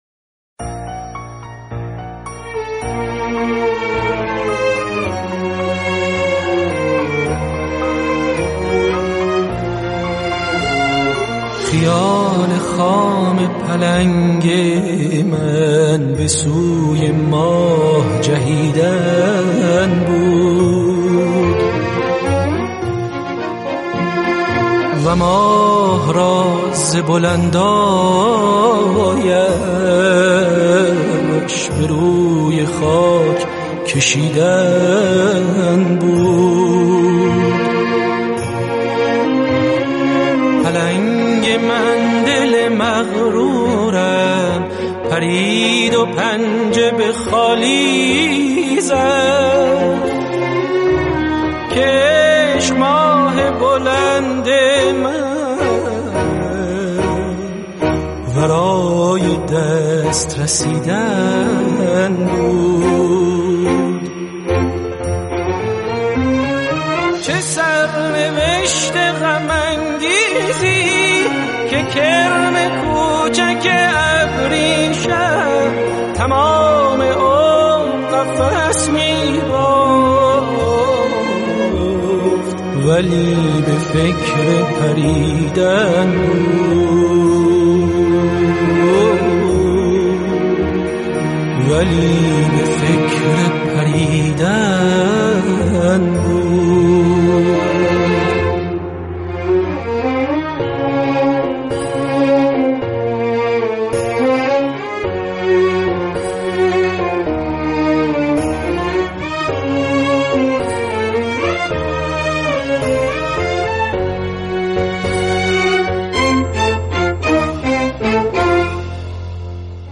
سبک این موزیک غمگین میباشد